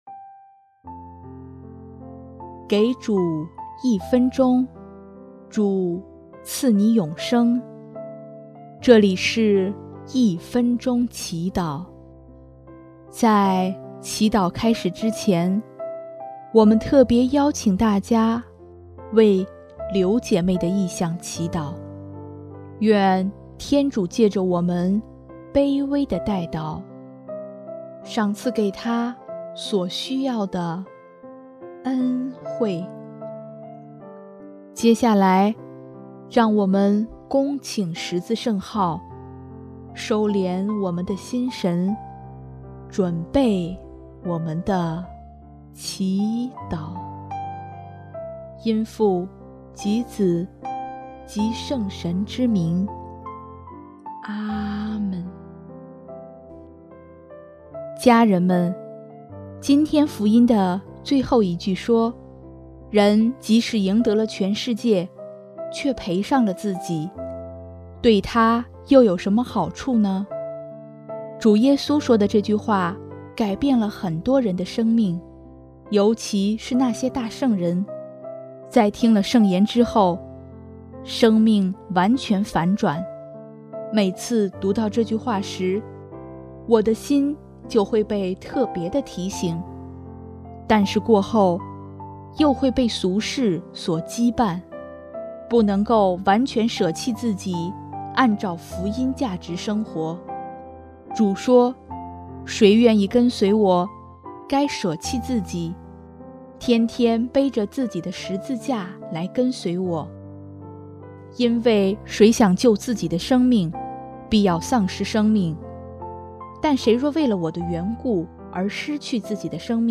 音乐：主日赞歌《舍弃》